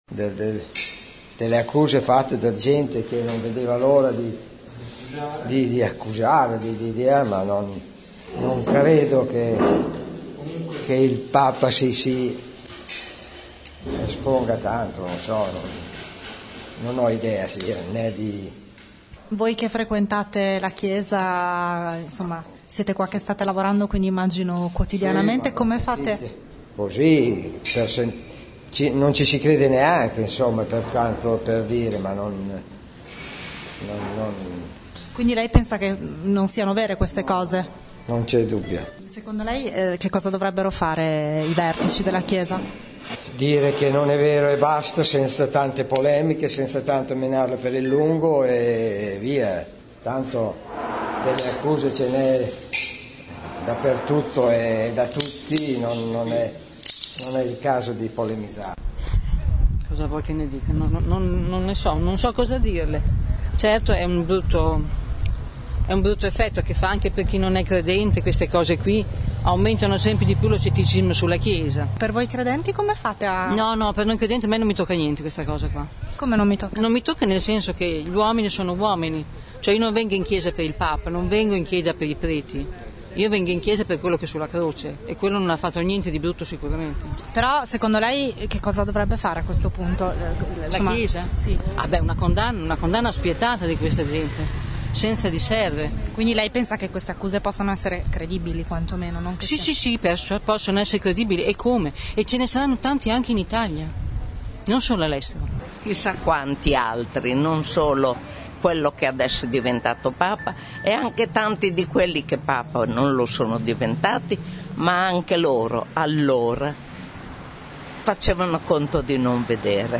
29 mar.- Abbiamo fatto un giro tra i fedeli e i parrocchiani della chiesa di San Giovanni Cottolengo, in via Marzabotto.
Ascolta le voci dei fedeli: